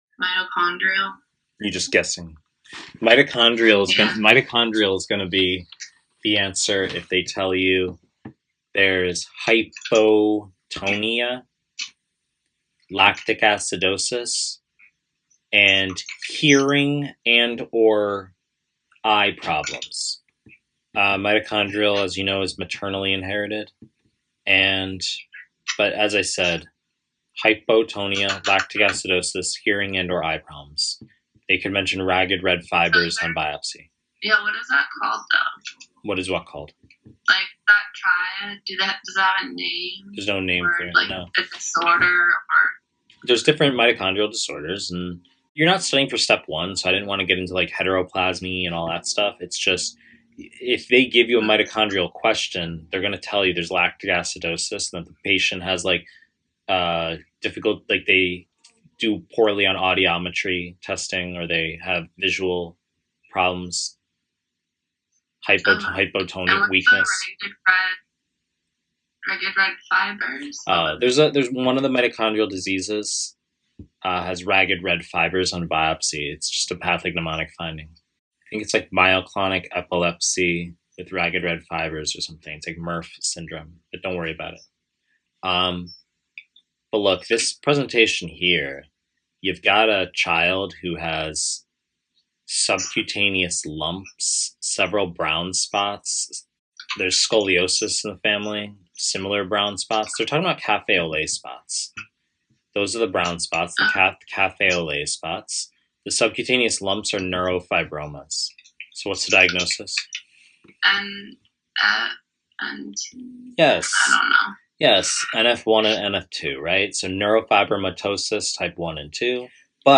Pediatrics / Pre-recorded lectures